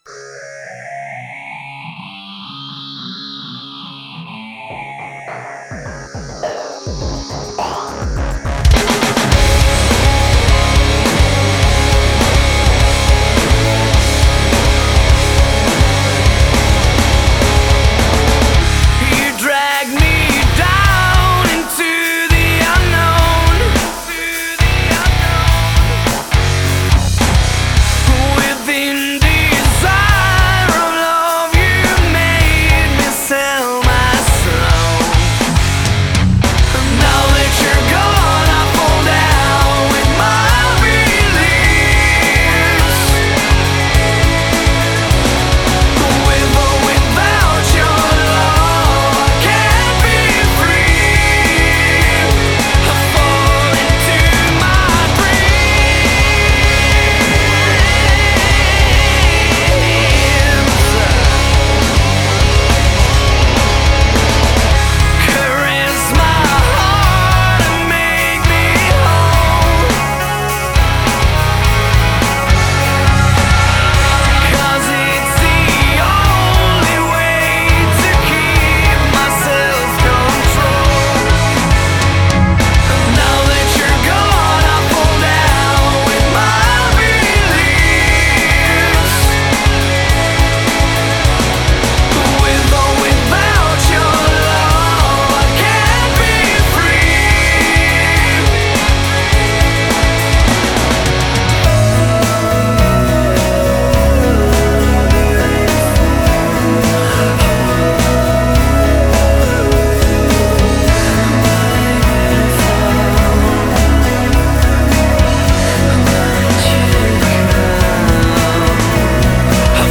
Gothic Metal